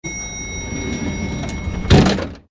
elevator_door_close.ogg